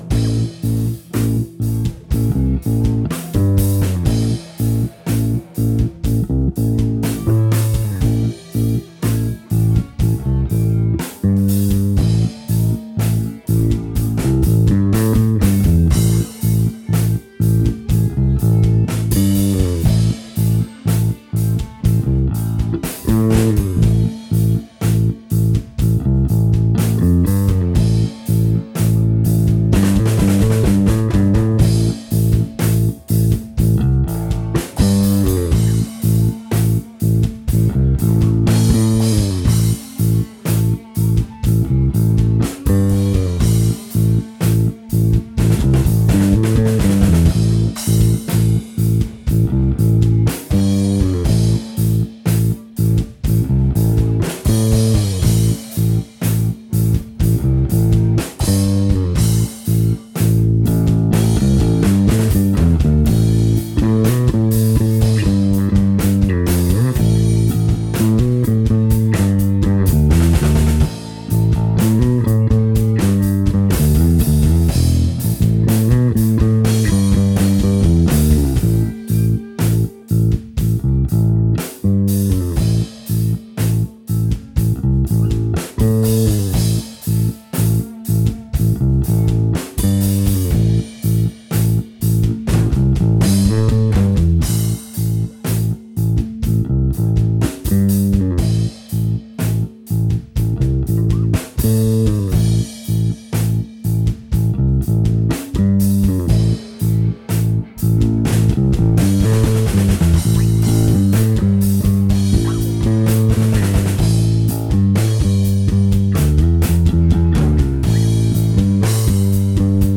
En esta sección GRAVELAND encontraréis periódicamente grabaciones en directo, en baja calidad (grabados en cassete con un micro colgado de un palo), con comentarios banales pisando inicios y finales de algunos cortes, discusiones sobre paridas insólitas, batallas de volúmenes, y bromas y referencias personales que a veces ni siquiera entendemos nosotros al hacer la reaudición.